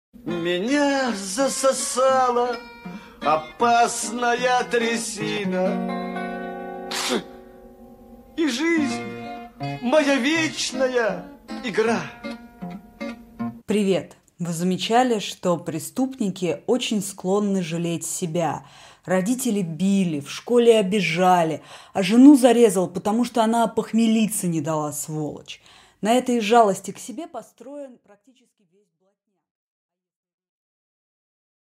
Аудиокнига Психология и пропаганда | Библиотека аудиокниг
Прослушать и бесплатно скачать фрагмент аудиокниги